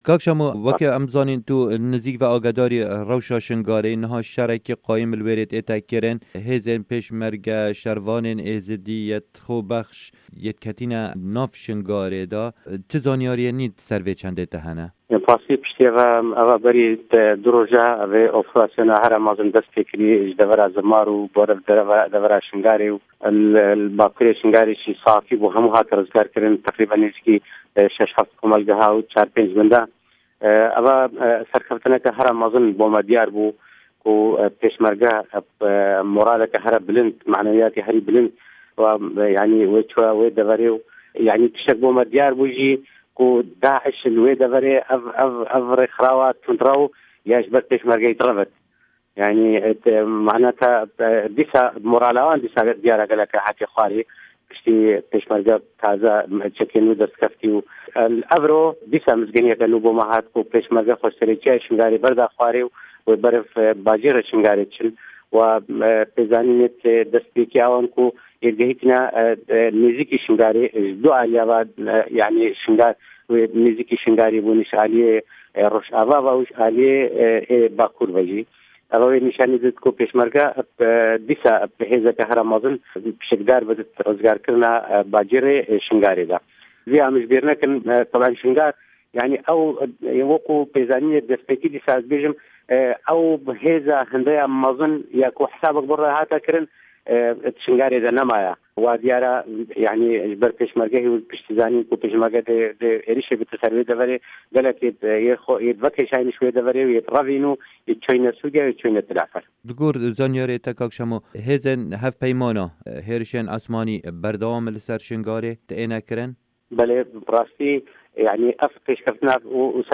hevpeyvênekê